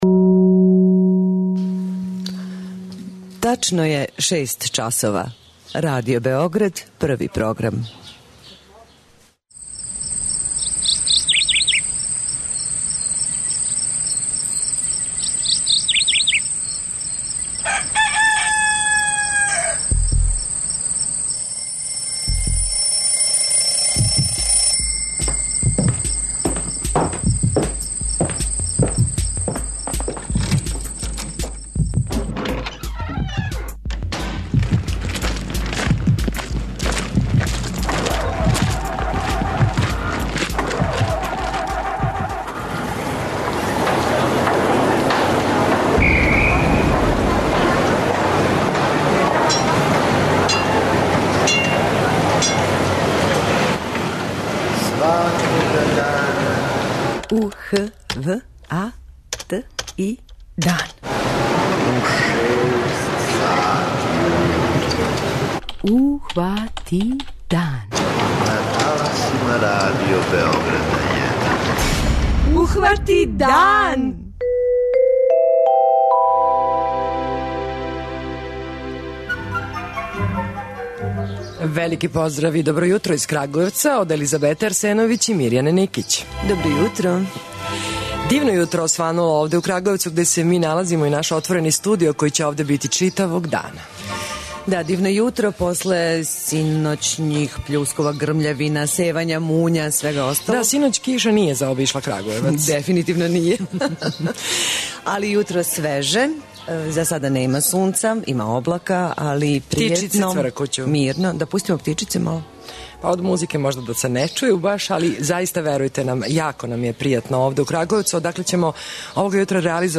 Овога јутра дан хватамо у КРАГУЈЕВЦУ, који је нова станица на турнеји Радио Београда 1 којом обележавамо 90 година постојања нашег и Вашег радија!